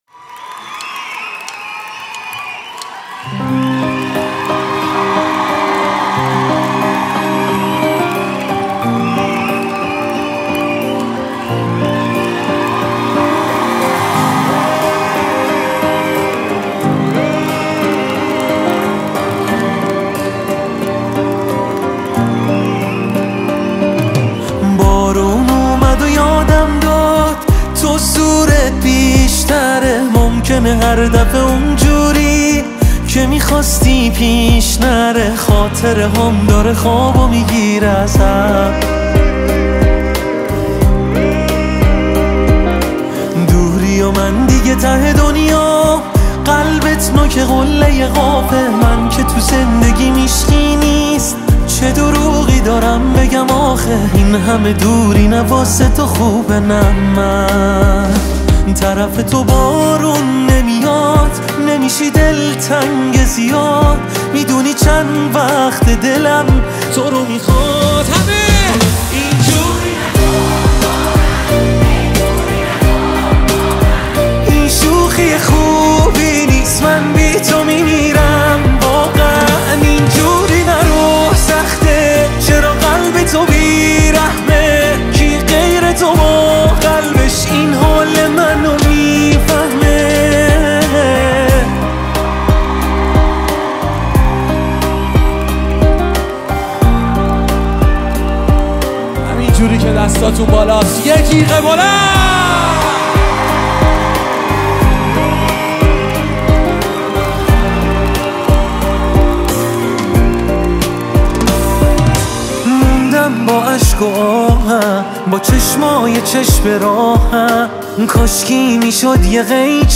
ژانر: پاپ + رپ
توضیحات: بهترین اجرای زنده در کنسرت های خوانندگان ایرانی